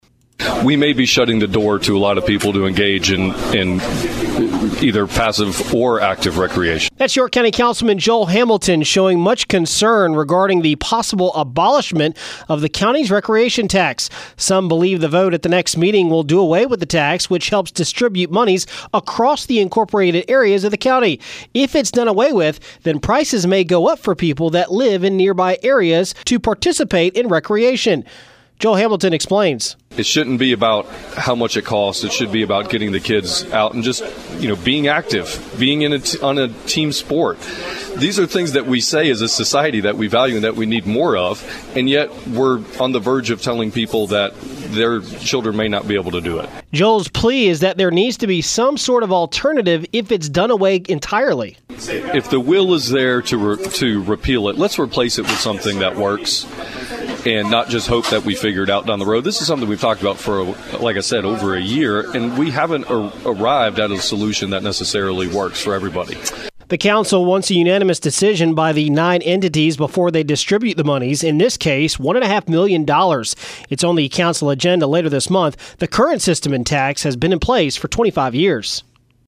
AUDIO: Councilman Joel Hamilton talks York County Rec Tax and the possibility of it being abolished